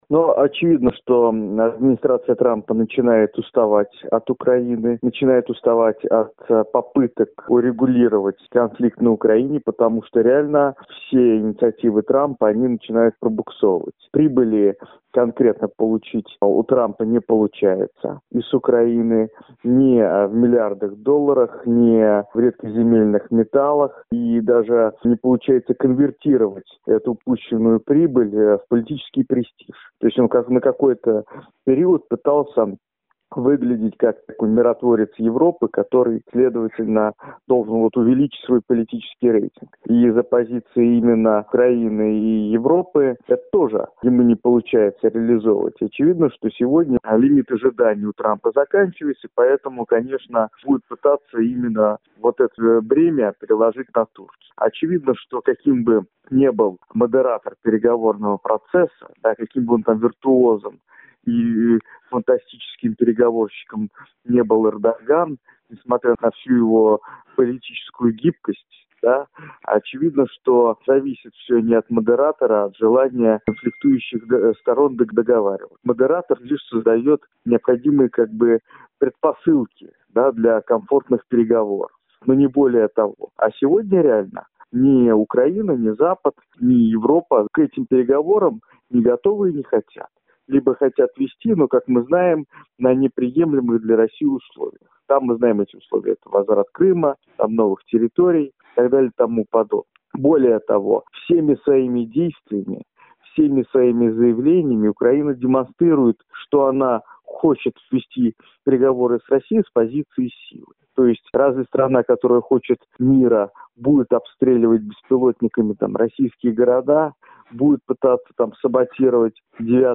ГЛАВНАЯ > Актуальное интервью